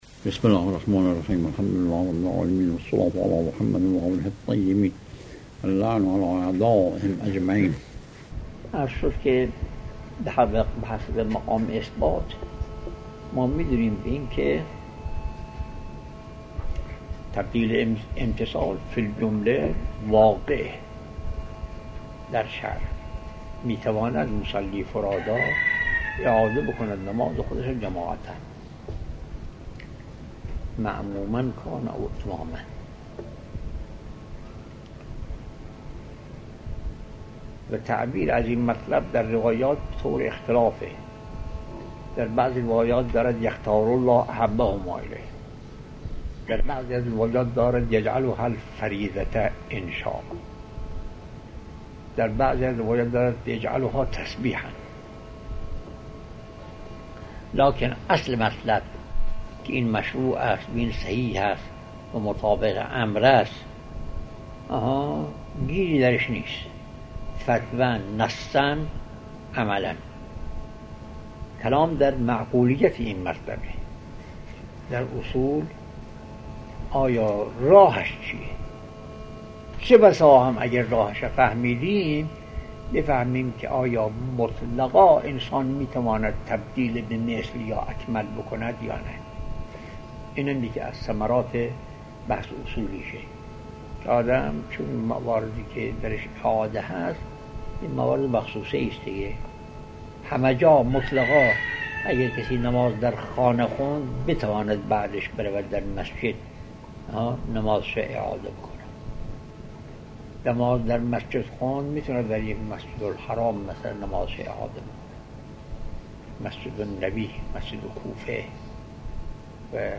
آيت الله بهجت - خارج اصول | مرجع دانلود دروس صوتی حوزه علمیه دفتر تبلیغات اسلامی قم- بیان